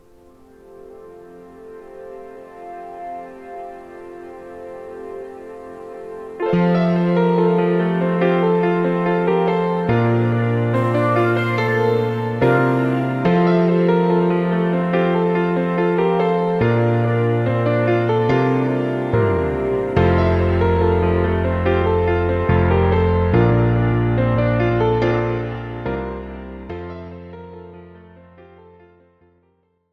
This is an instrumental backing track cover.
• Key – F
• Without Backing Vocals
• No Fade